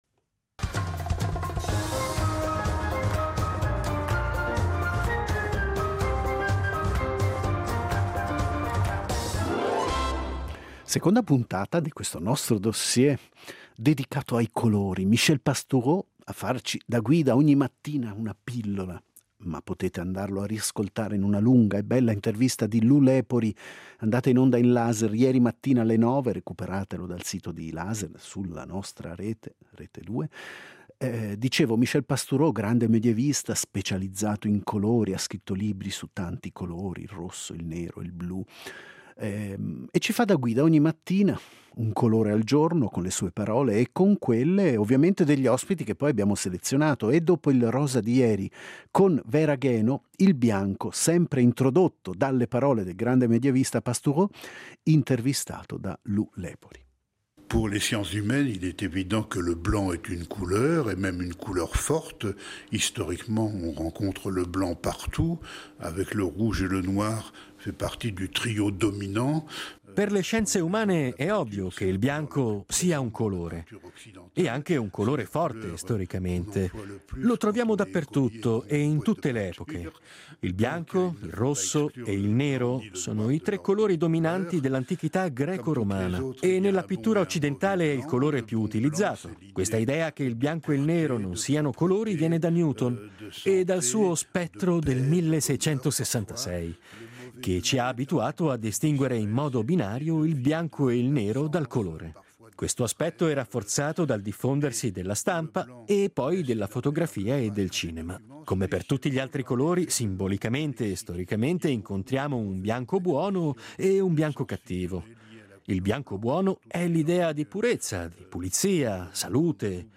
Il secondo colore è stato il bianco, che abbiamo affidato a Vinicio Capossela . Intervistato